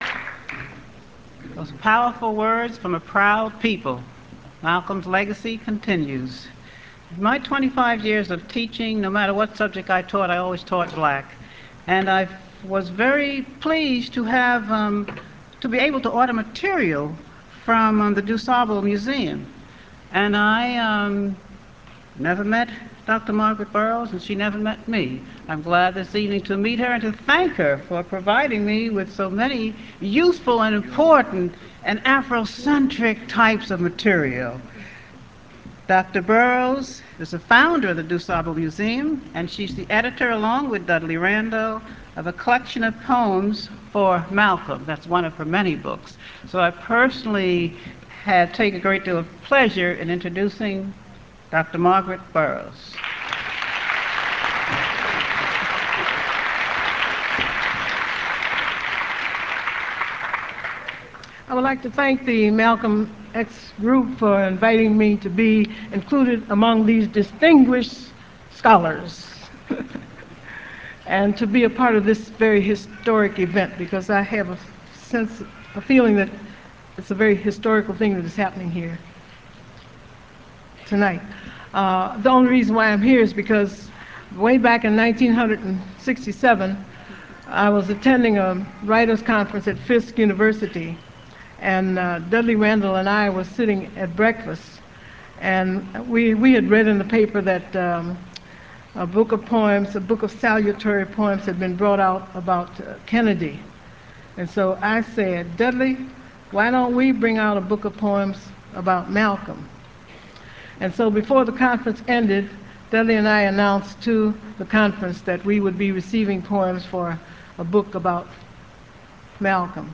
MALCOLM X: THE CONFERENCE
Dr. Margaret Burroughs, founder of the DuSable Museum of African American History, co-editor of For Malcolm: Poems of Celebration